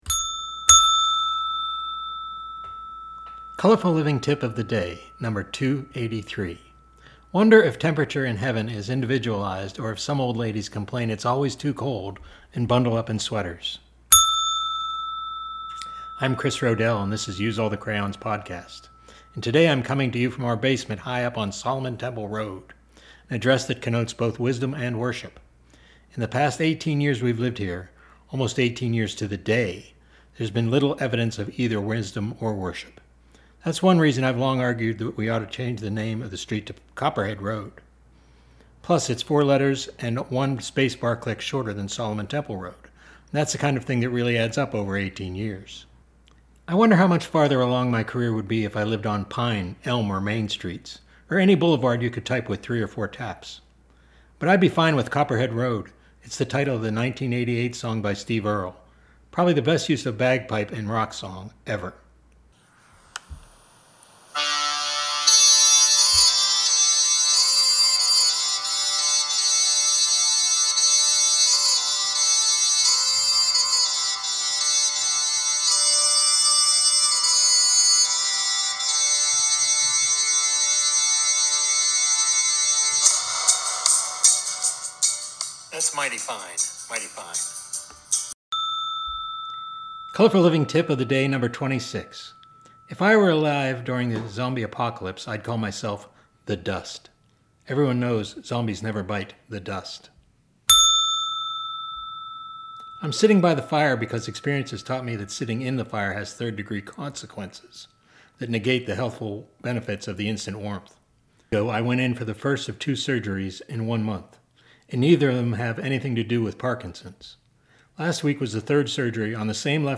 I've done more than 2,200 blog posts from 2007 to 2024. Here is me reading three of them and riffing on a recent surgery.